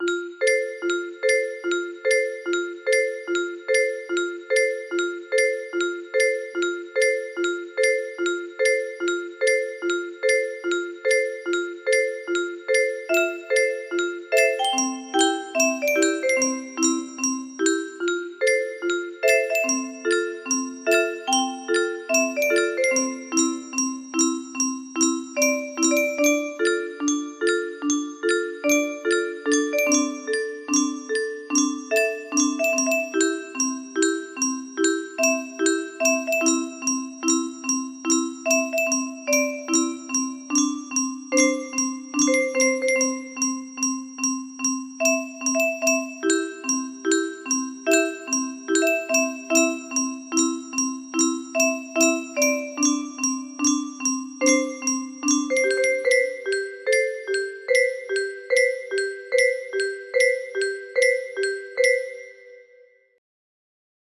sorry if the timing is a bit off